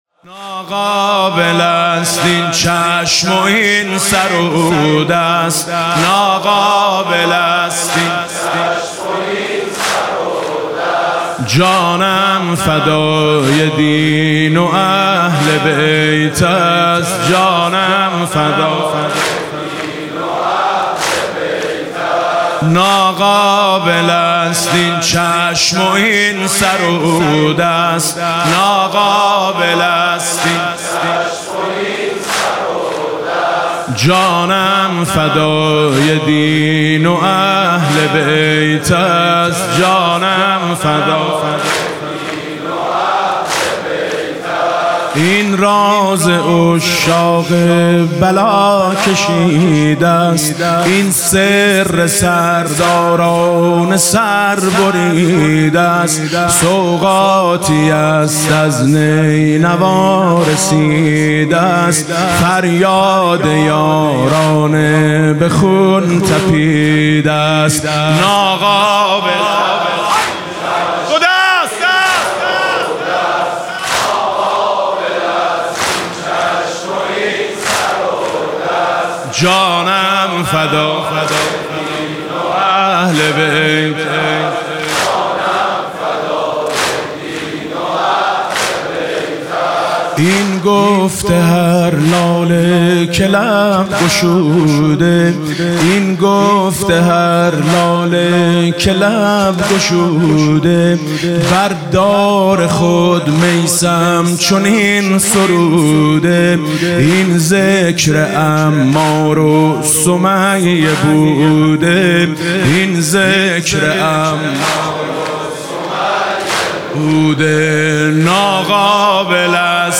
دانلود مداحی دهه اول محرم 1402
شب هشتم محرم 1402